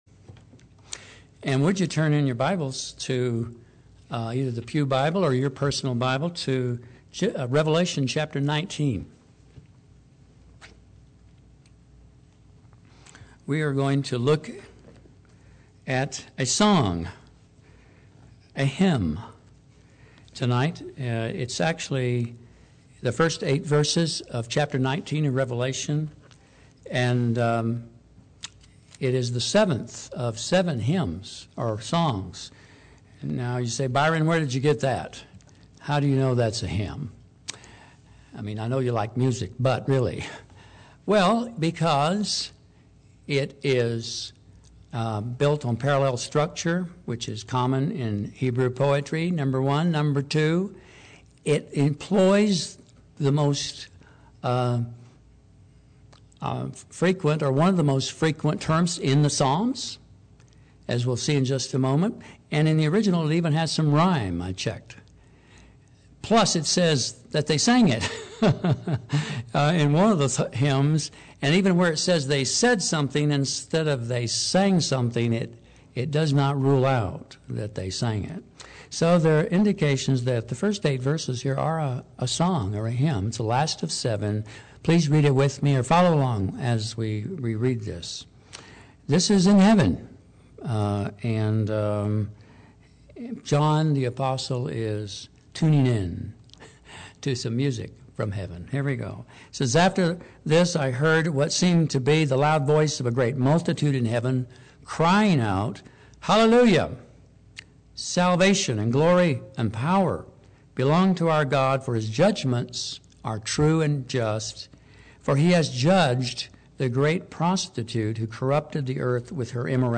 Play Sermon Get HCF Teaching Automatically.
The Alarming New Age Surveillance and the Coming Judgement Wednesday Worship - 07/10/13 Speaker: Scripture: Revelation 19:1-8 Downloads Right-click to download Audio Share this: